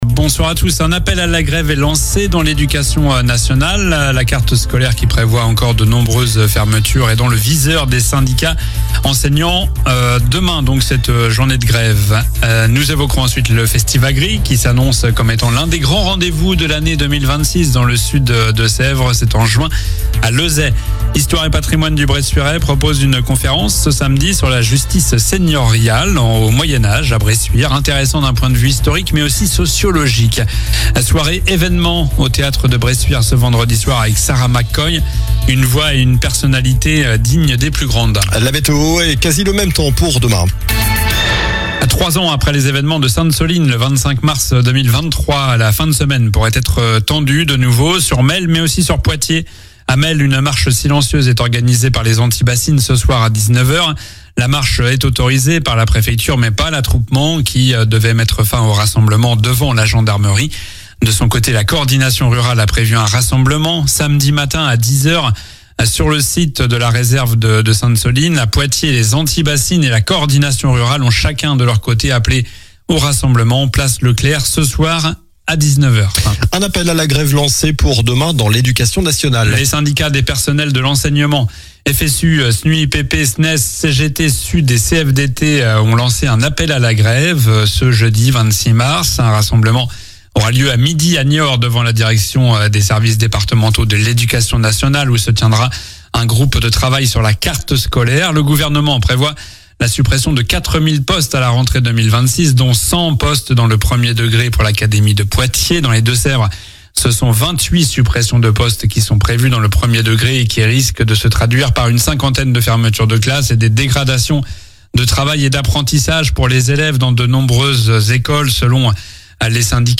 Journal du mercredi 25 mars (soir)